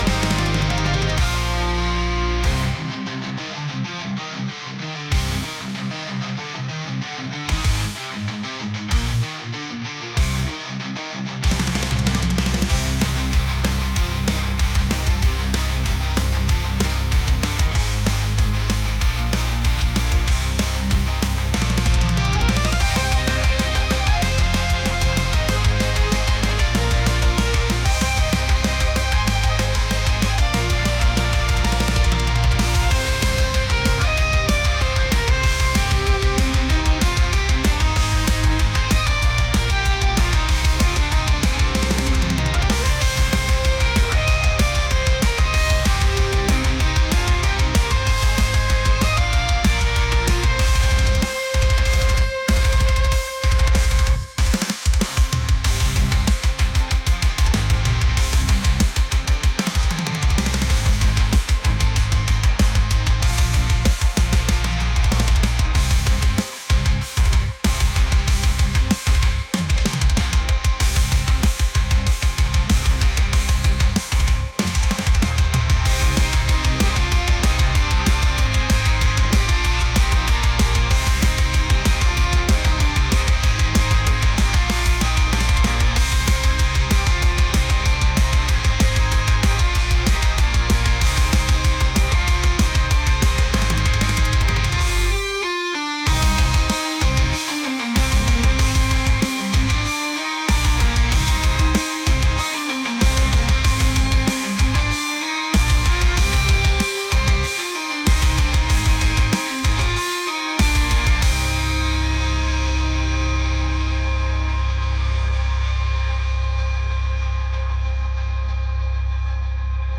metal | heavy | aggressive